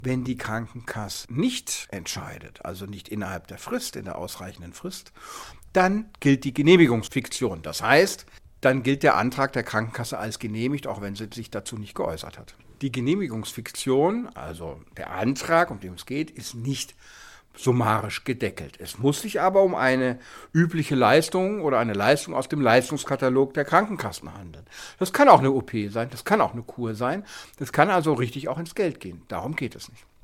O-Ton: Was tun, wenn die Krankenkasse nicht zahlt?
DAV, O-Töne / Radiobeiträge, Ratgeber, Recht, , , , ,